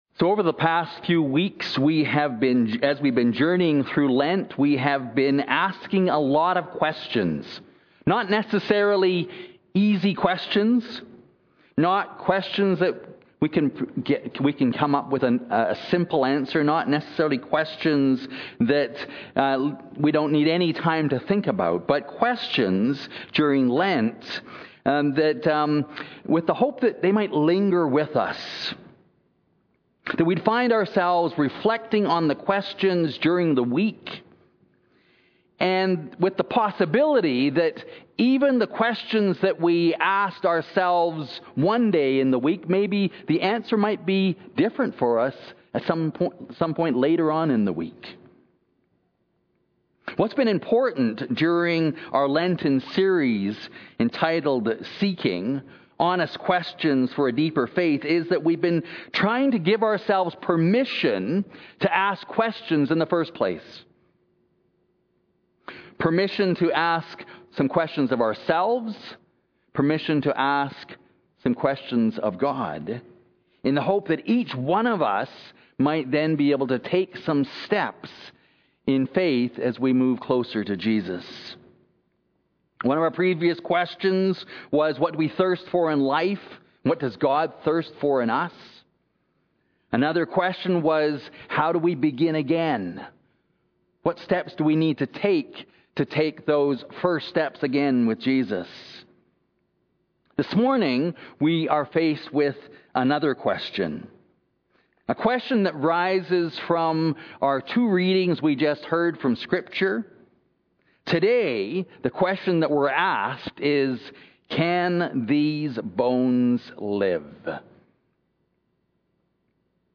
- Holy Trinity Anglican Church (Calgary)